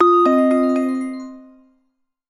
En Güzel 2026 Bildirim Sesleri İndir - Dijital Eşik